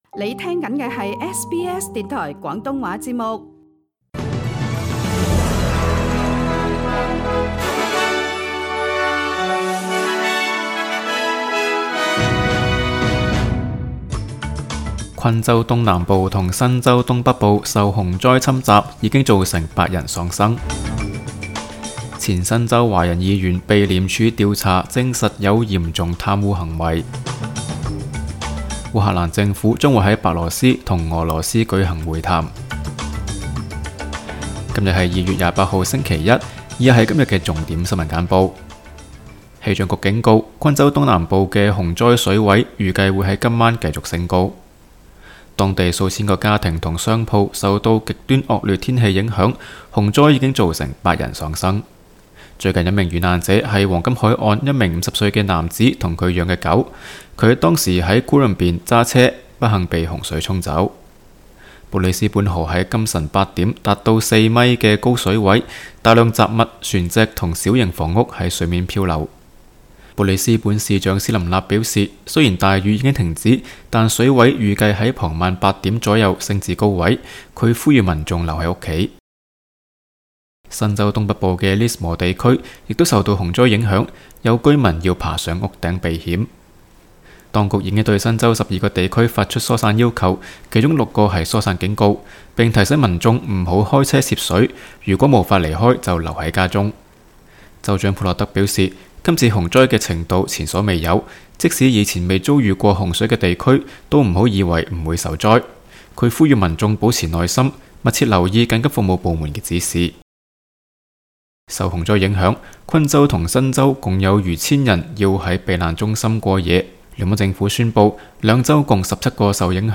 SBS 新闻简报（2月28日）